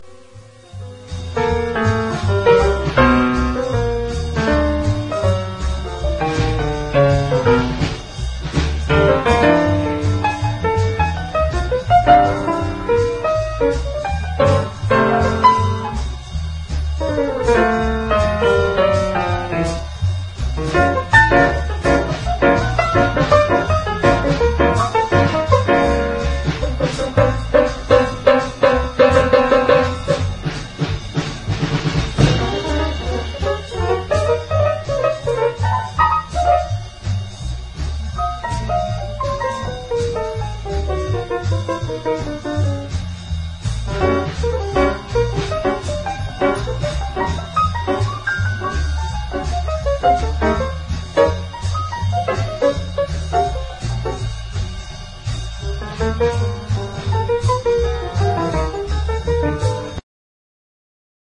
JAZZ / FREE / EXPERIMENTAL / POST BOP